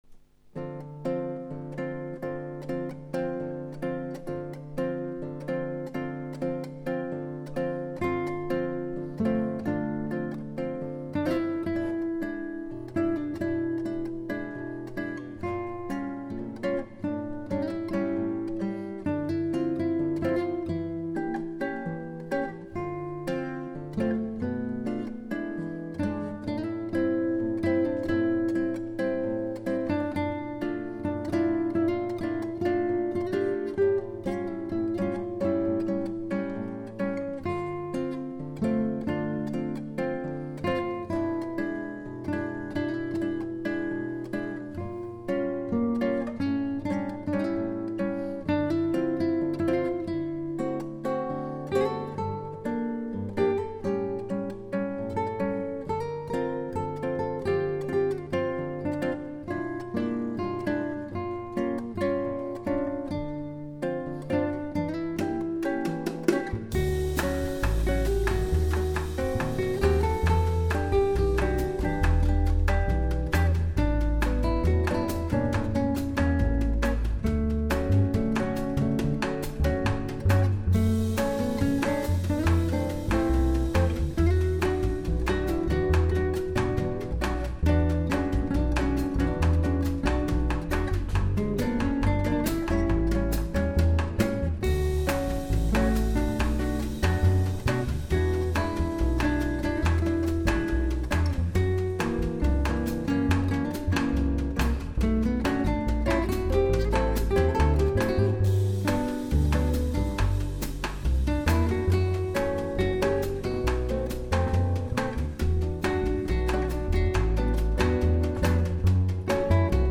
Gypsy